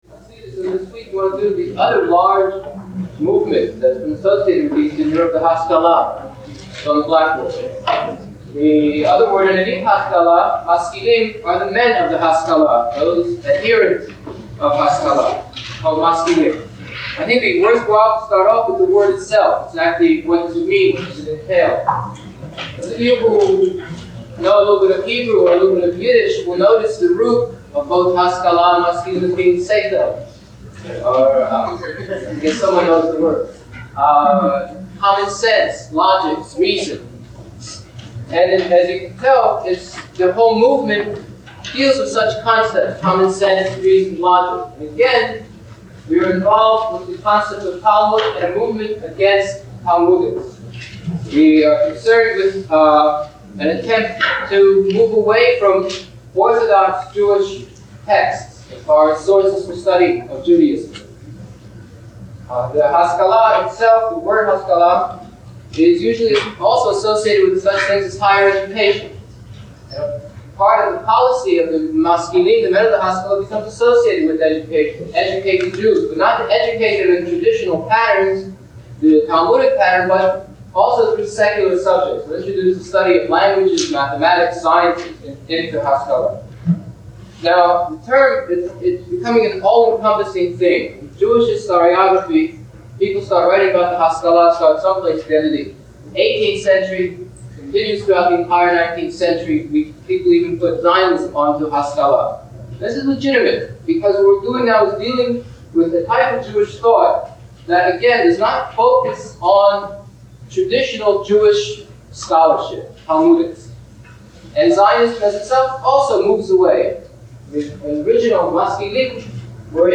Lecture #15 - March 24, 1971